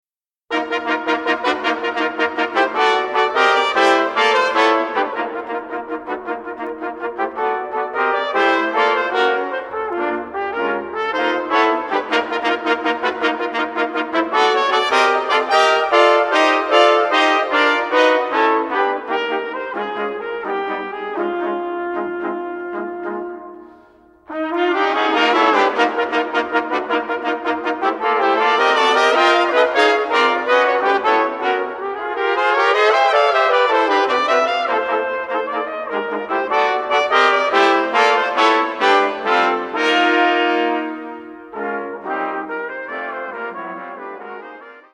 für 4 Trompeten / Flügelhörner